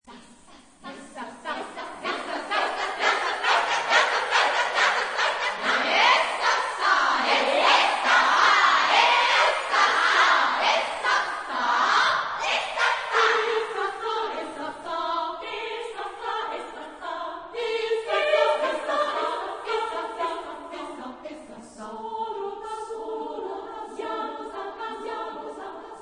Genre-Stil-Form: Chorwerk ; weltlich
Chorgattung: SSSAA  (5 Frauenchor Stimmen )
Tonart(en): frei